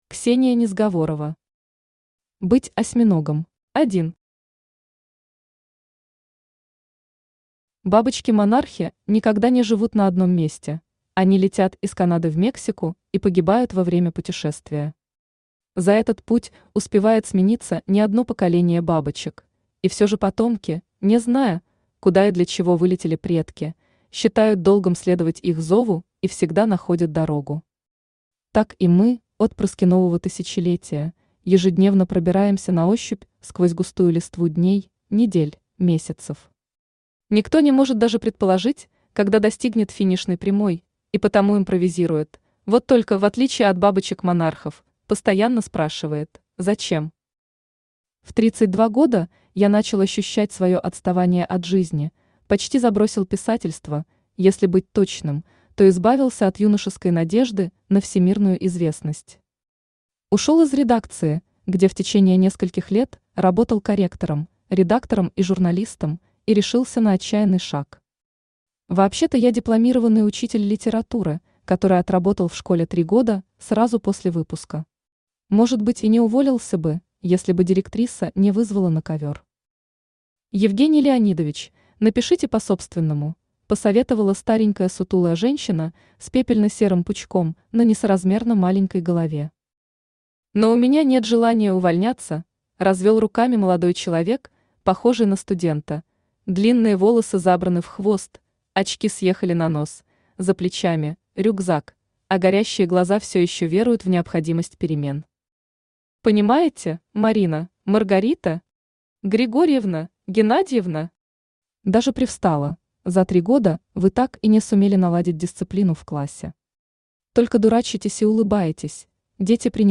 Аудиокнига Быть осьминогом | Библиотека аудиокниг
Aудиокнига Быть осьминогом Автор Ксения Викторовна Незговорова Читает аудиокнигу Авточтец ЛитРес.